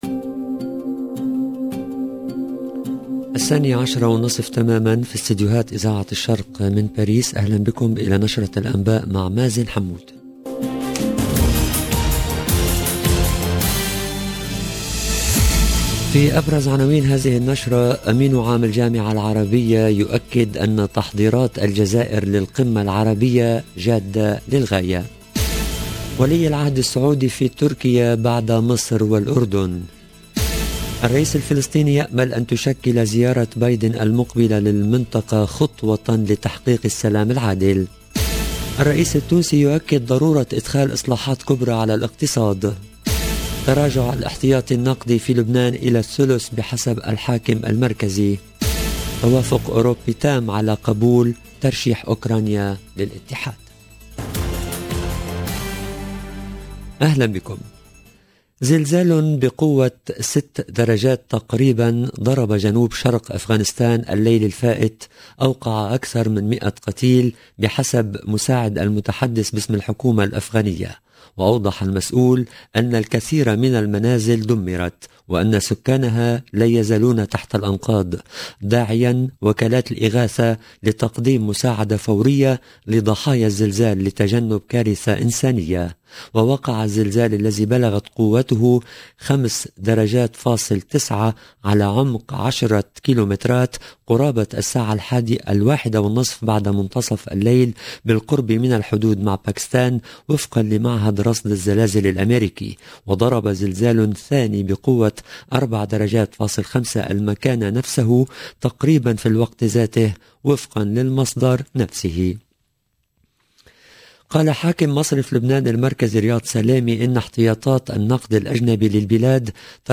LE JOURNAL EN LANGUE ARABE DE MIDI 30 DU 22/06/22